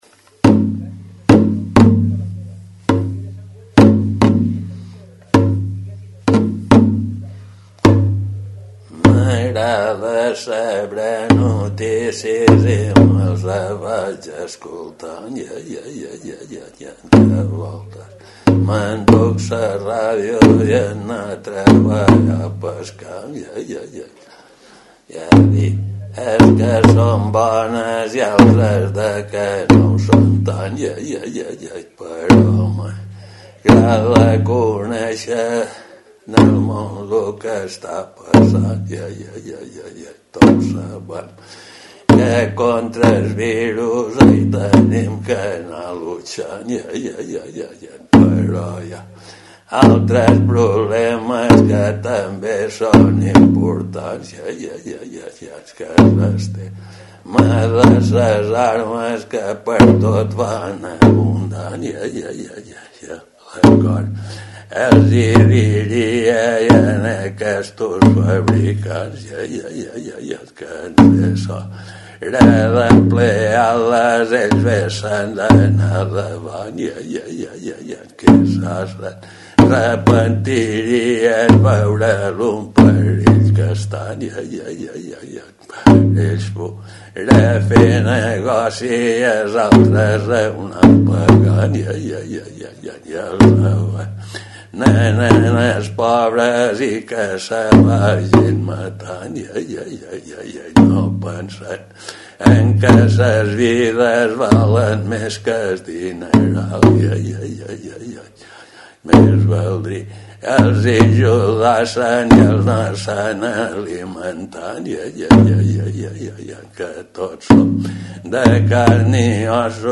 presenta a Ràdio Illa la seva darrera creació en estil redoblat, Es negoci de ses armes.